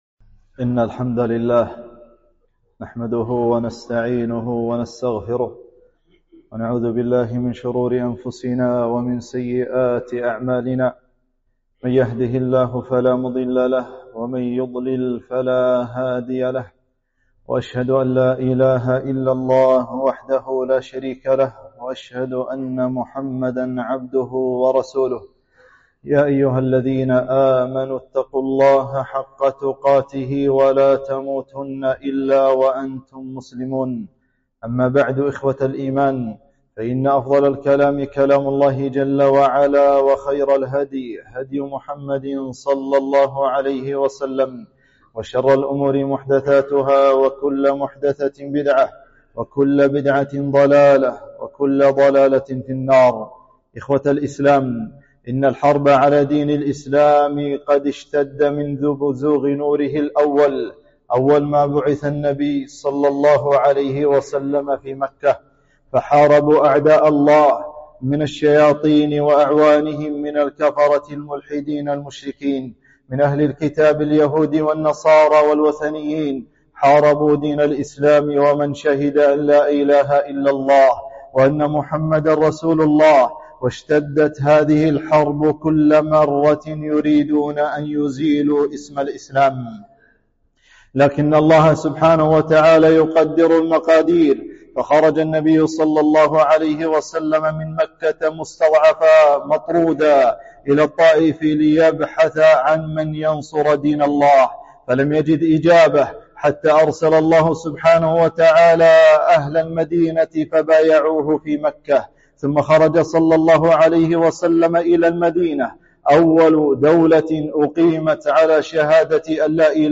خطبة - العداء على الإسلام ونصر الله قريب